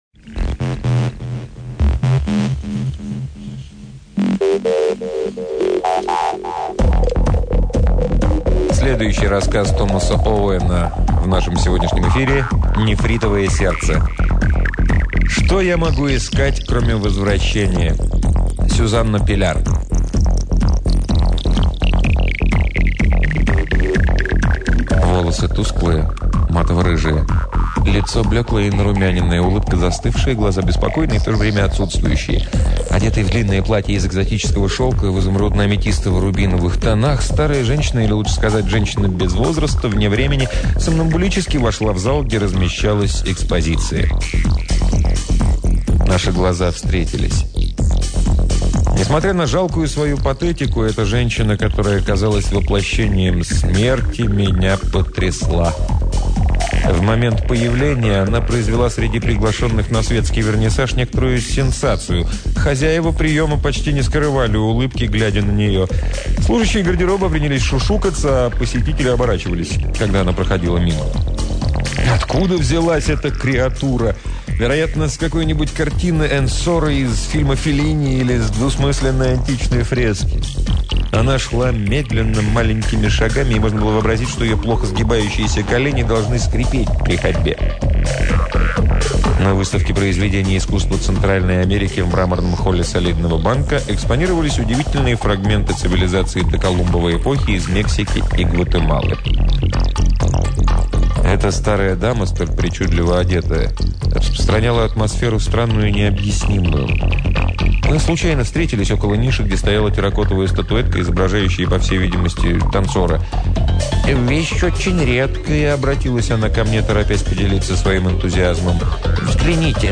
Аудиокнига Томас Оуэн — Нефритовое сердце
Аудиокниги передачи «Модель для сборки» онлайн